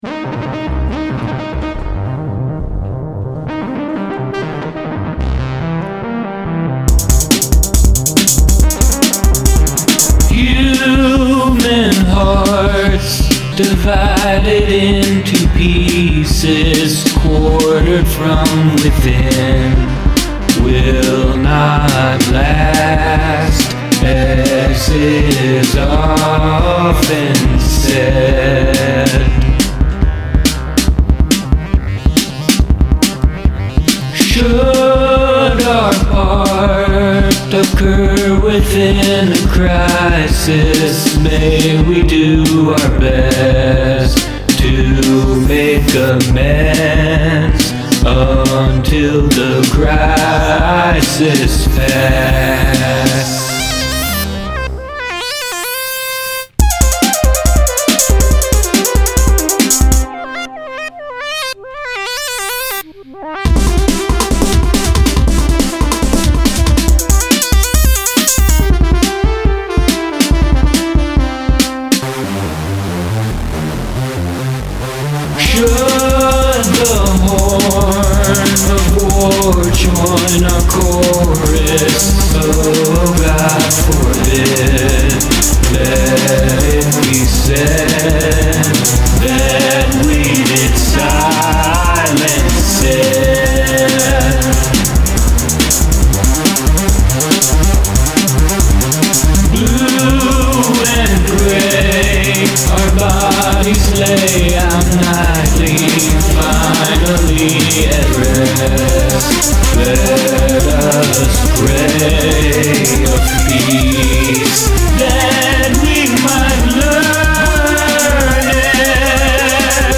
verse verse instrumental verse verse
i wrote this by mucking around in logic. i took some harpsichord midi files and changed them to synth parts. the synths are monophonic and they're trying to play a polyphonic part which creates all sorts of weird leaps, which i find pleasing. the words took me a while. i've been watching a lincoln documentary and was inspired by his "a house divided" speech.